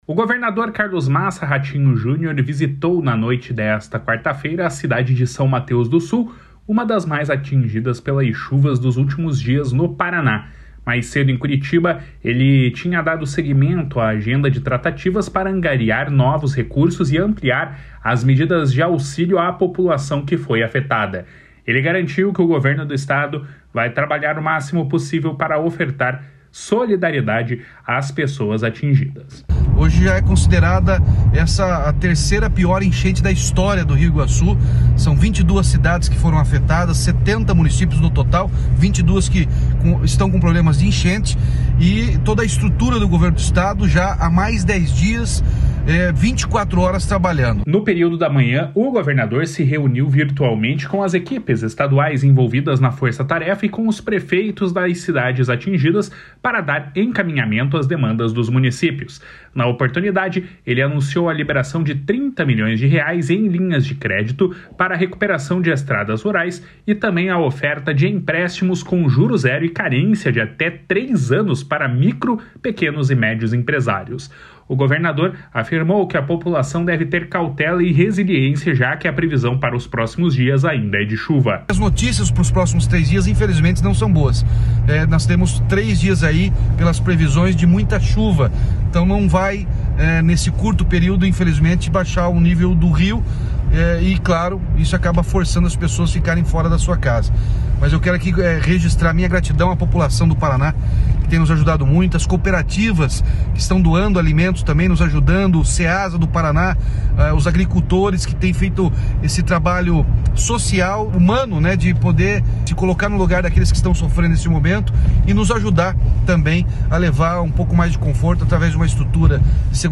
// SONORA RATINHO JÚNIOR //